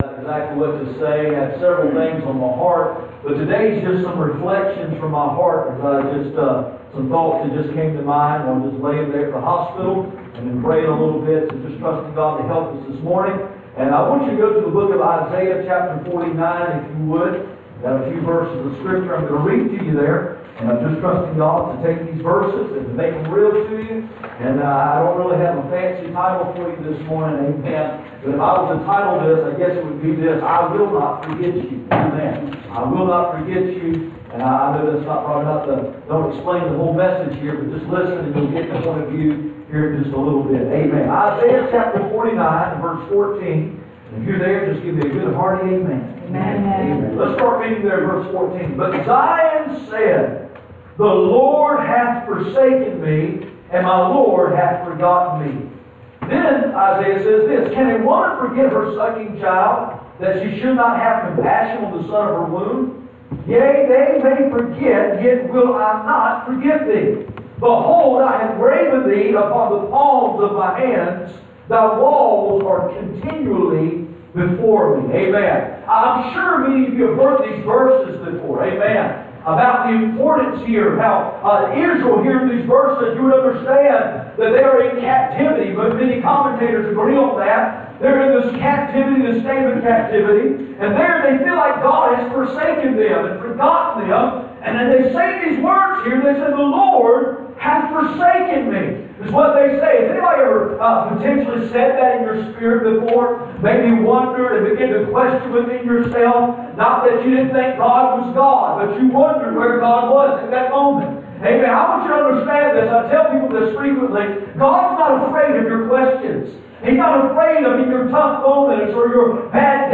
None Passage: Isaiah 49:14-18 Service Type: Sunday Morning %todo_render% « Remember to Forget